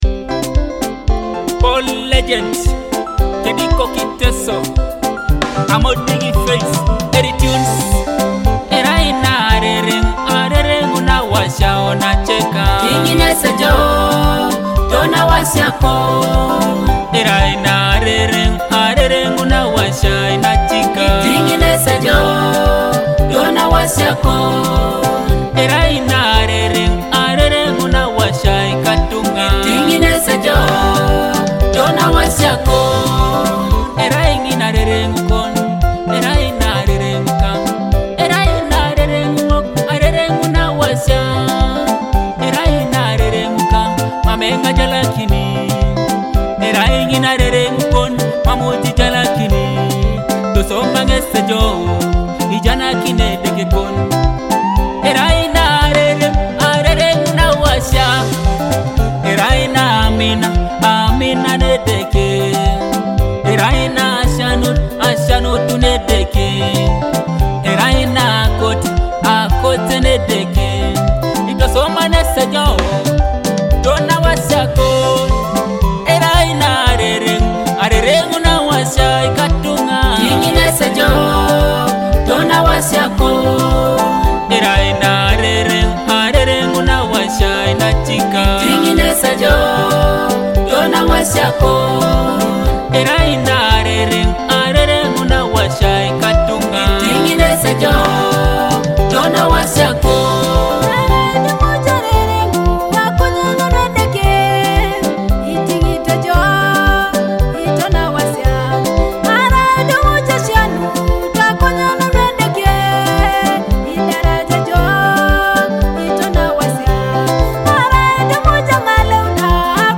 Teso gospel song
uplifting and soul-stirring
gospel anthem